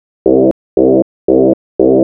TSNRG2 Off Bass 023.wav